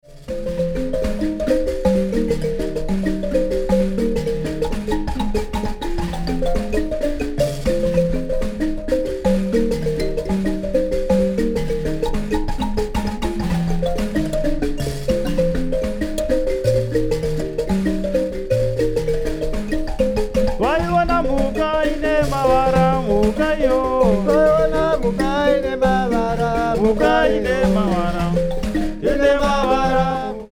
B flat nyamaropa tuning.